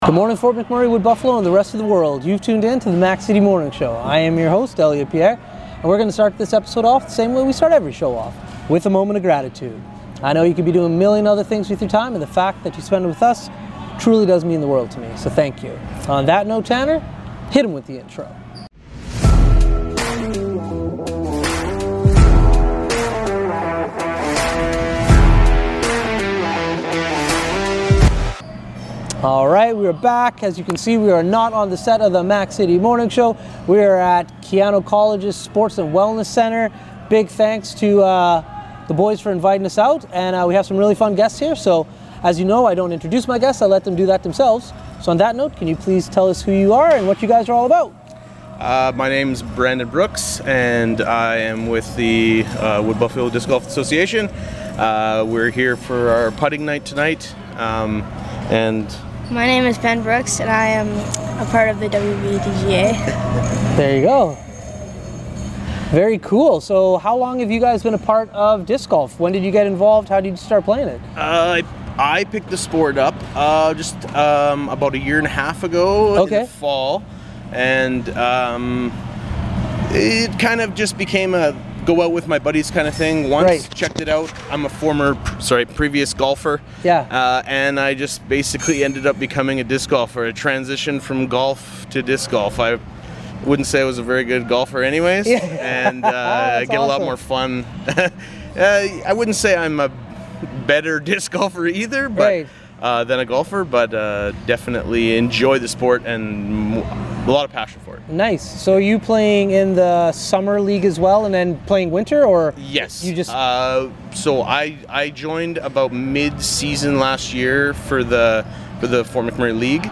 We take the morning show on the road, tune in to find out what we are up to!